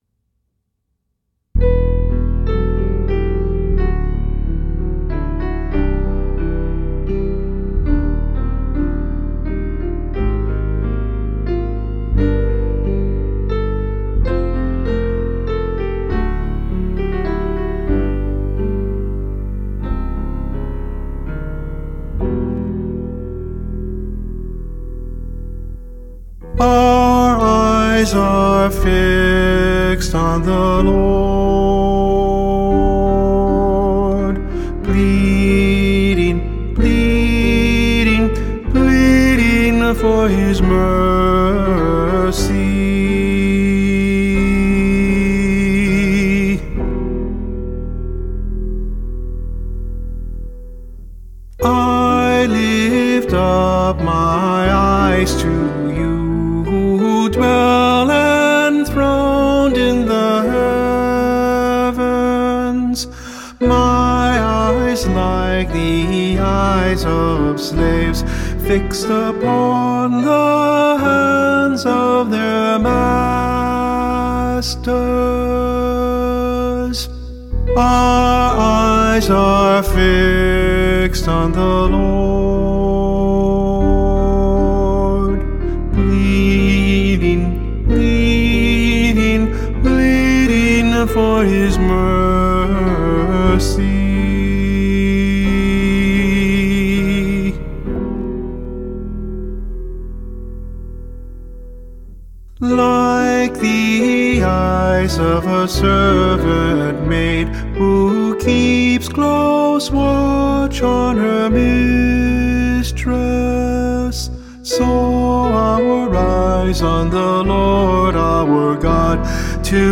Responsorial Psalms